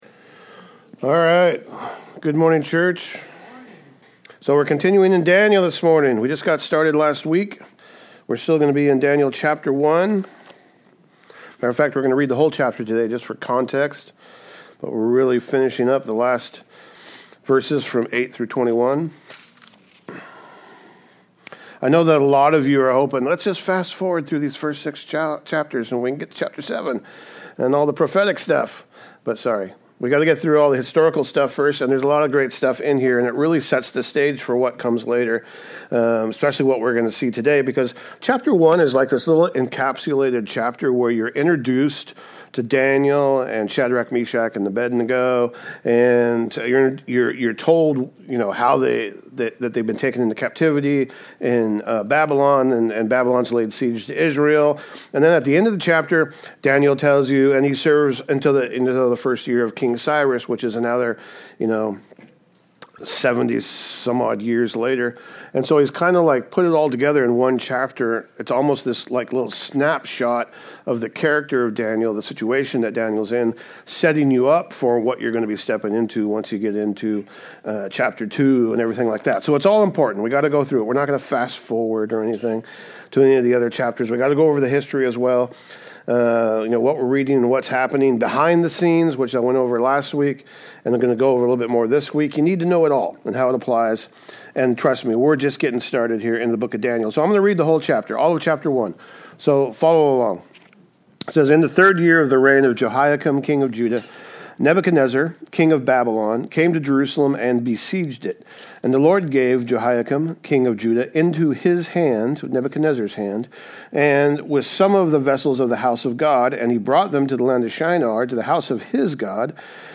Sermons | Calvary Chapel Snohomish | Snohomish, Wa